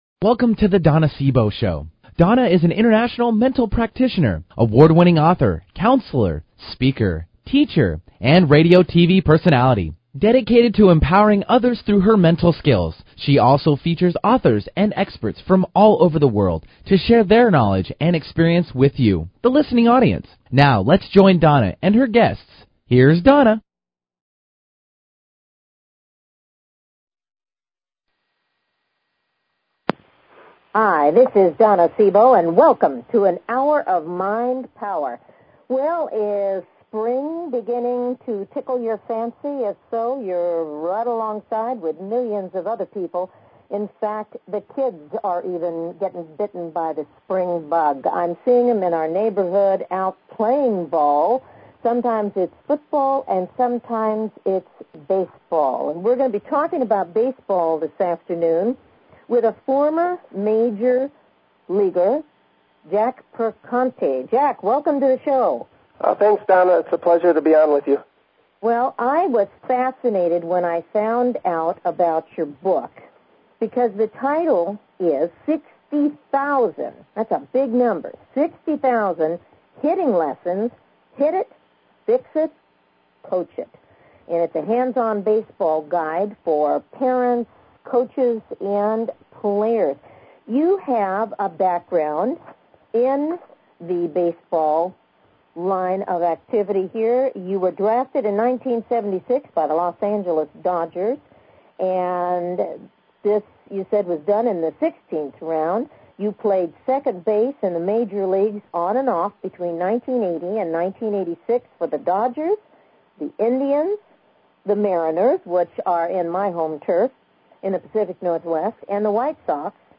Talk Show Episode, Audio Podcast
On site broad cast at the Psychic Spectrum Expo in Seattle. Lots to talk about and wonderful people to chat with.